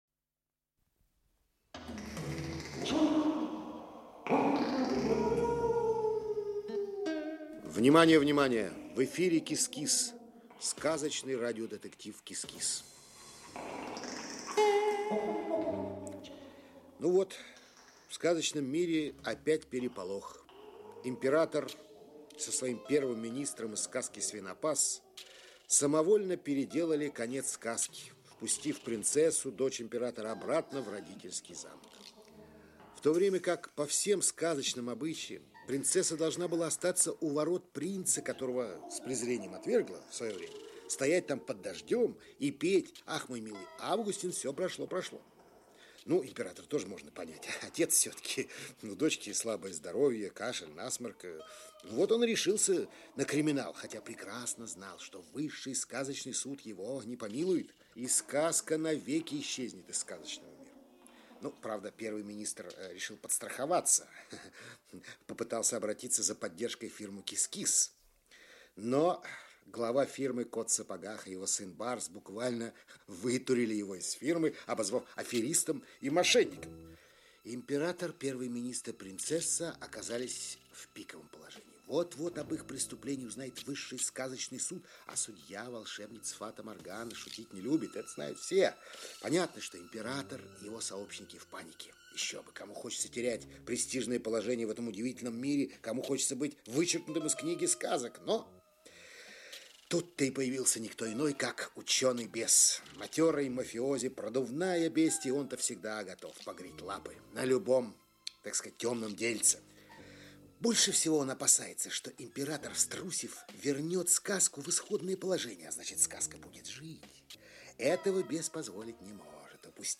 Аудиокнига КИС-КИС. Дело № 12. Часть 2 | Библиотека аудиокниг